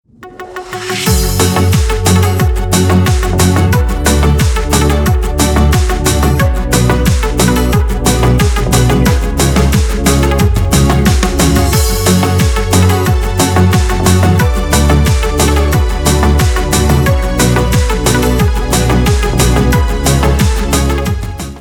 Шансон рингтоны
Рингтон без слов на телефон